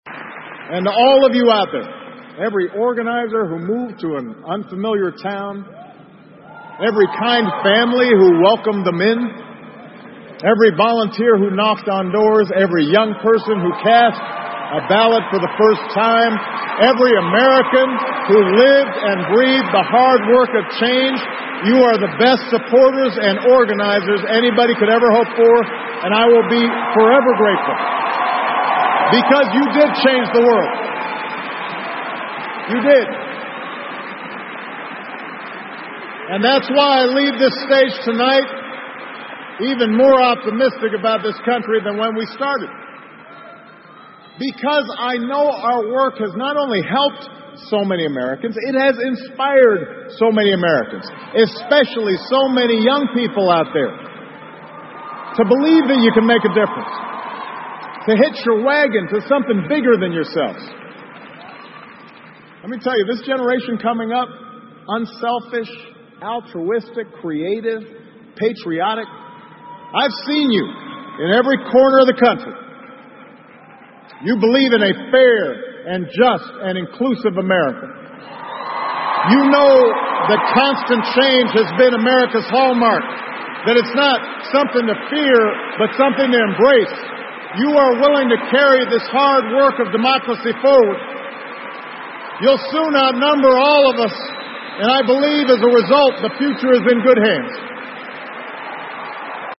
奥巴马每周电视讲话：美国总统奥巴马告别演讲(22) 听力文件下载—在线英语听力室